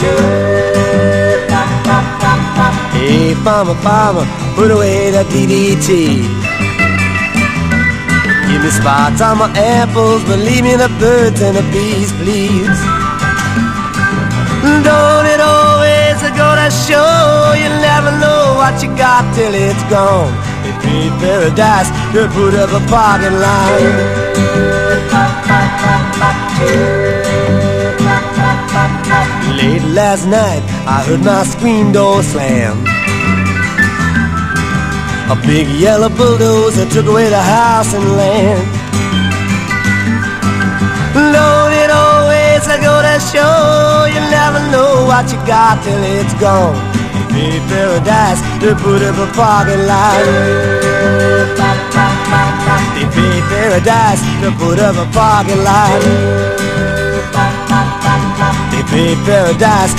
ROCK / 60'S / FOLK / FOLK ROCK